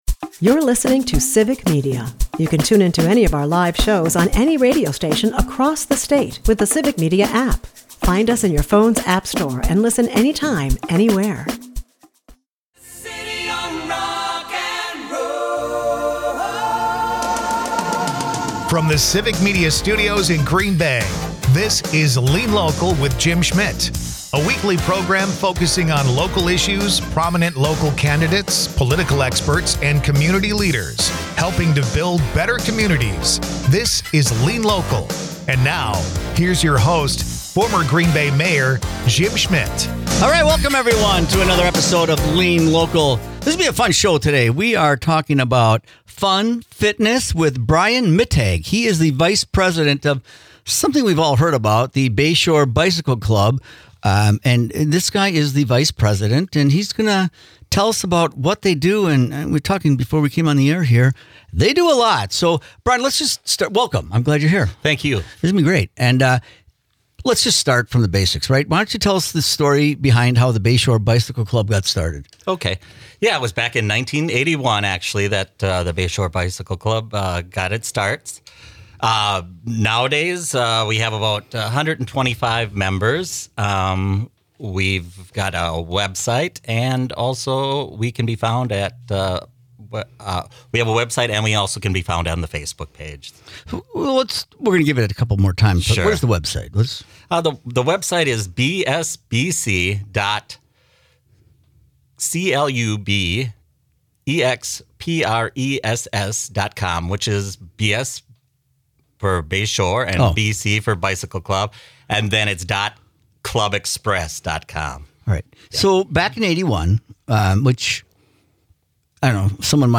In this episode of Lean Local, host Jim Schmitt, former Mayor of Green Bay, discusses local community activities and initiatives.
The episode emphasizes community engagement, health, and sustainable transport solutions. Lean Local is a part of the Civic Media radio network and airs Sunday's from 1-2 PM on WGBW .